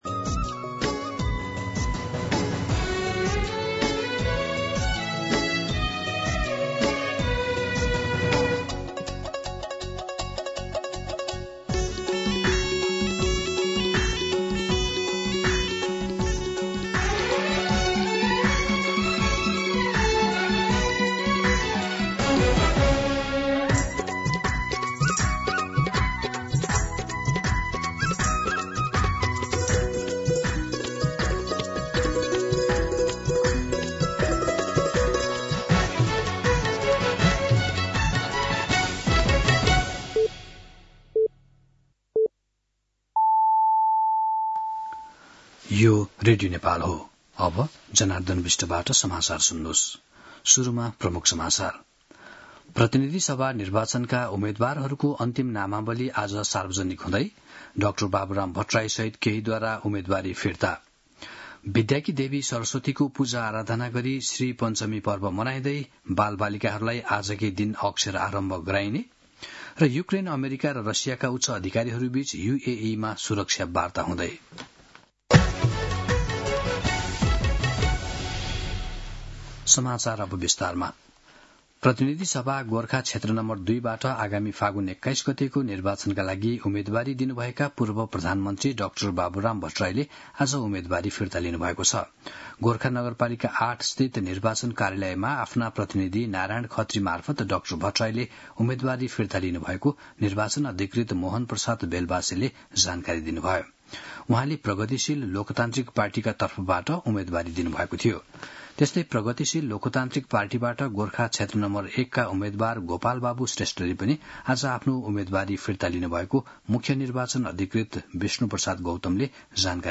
दिउँसो ३ बजेको नेपाली समाचार : ९ माघ , २०८२
3-pm-News-10-9.mp3